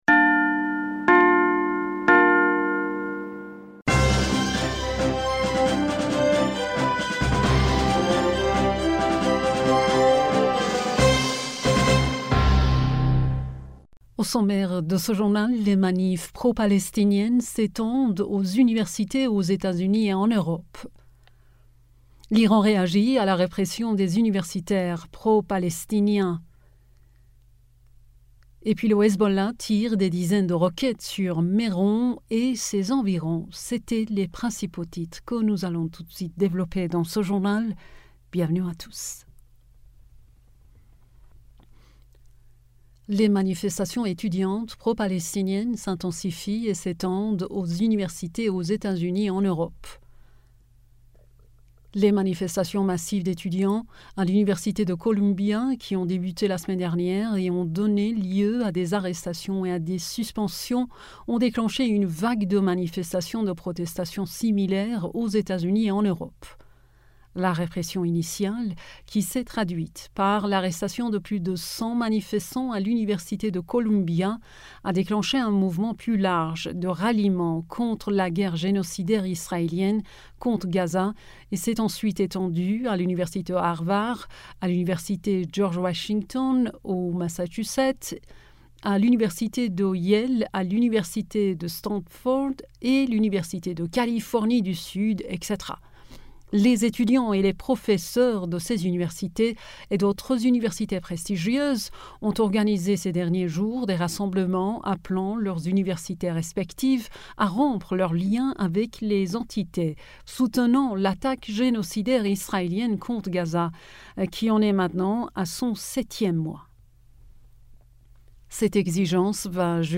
Bulletin d'information du 28 Avril